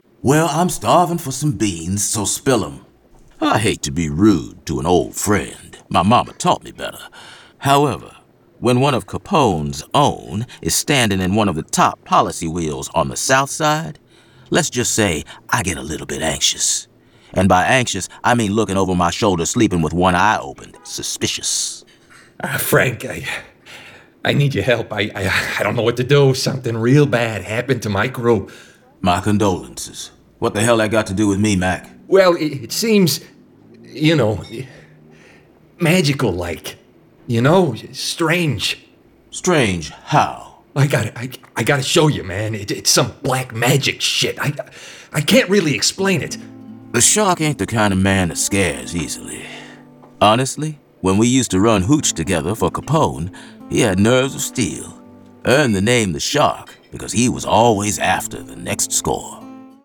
Full Cast. Cinematic Music. Sound Effects.
[Dramatized Adaptation]
Adapted from the graphic novel and produced with a full cast of actors, immersive sound effects and cinematic music.